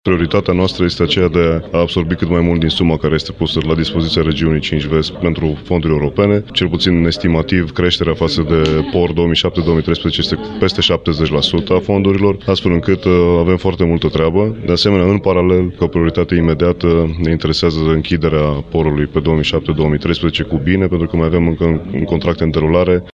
Timp de un an de zile, acesta va fi coordonat de Adrian David,  preşedintele Consiliului Judeţean Hunedoara: